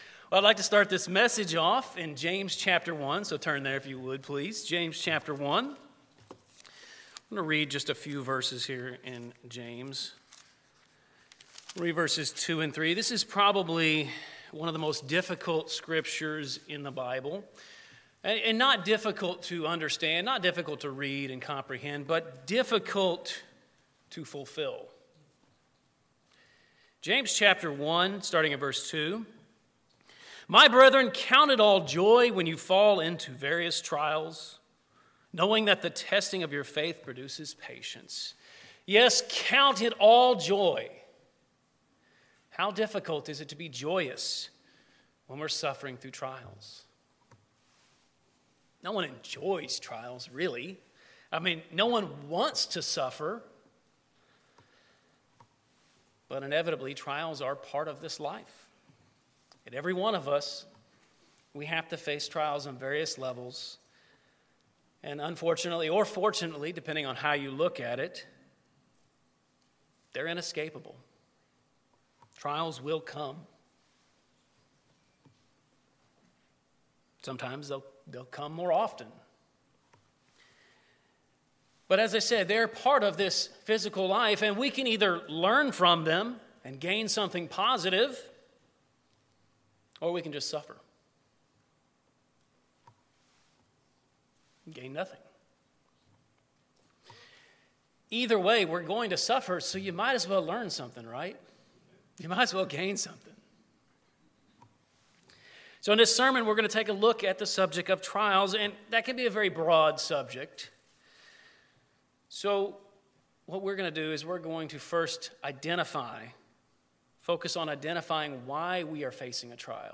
Sermons
Given in East Texas